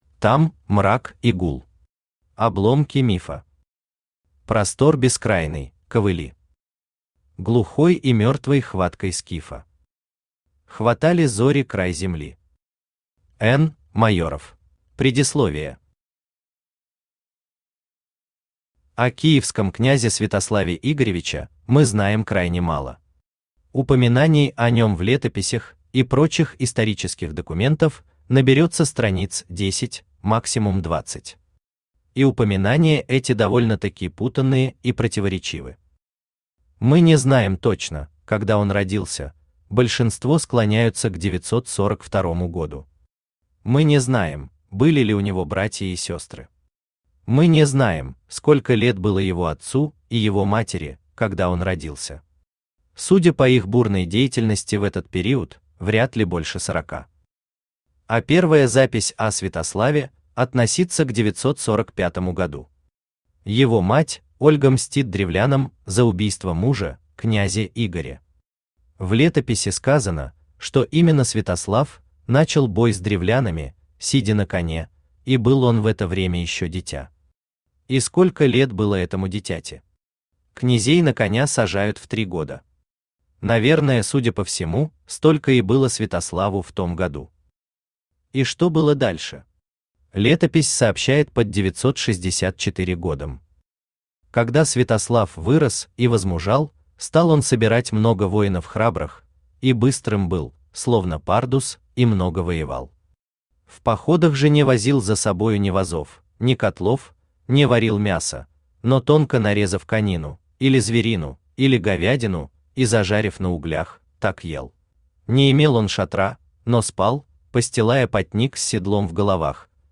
Аудиокнига Обломки мифа, или «Мёртвые сраму не имут» | Библиотека аудиокниг
Aудиокнига Обломки мифа, или «Мёртвые сраму не имут» Автор Анатолий Алексеевич Гусев Читает аудиокнигу Авточтец ЛитРес.